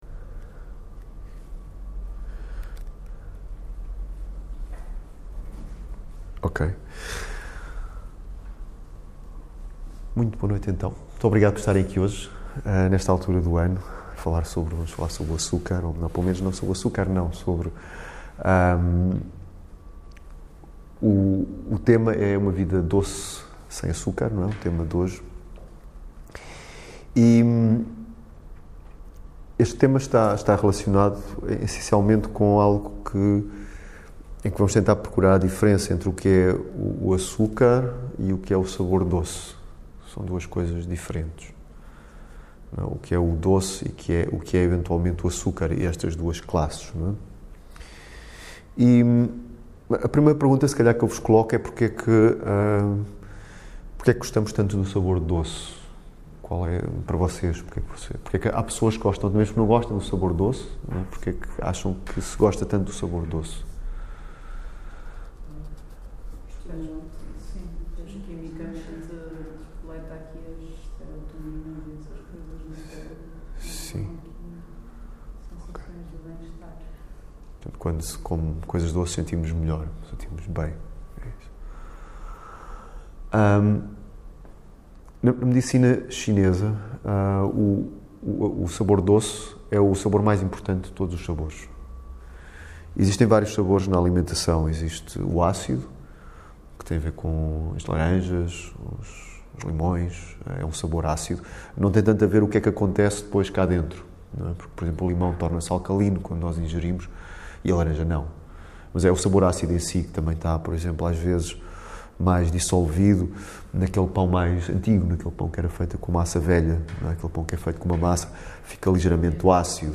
O papel do doce nas nossas vidas, numa palestar realizada no espaço regenerar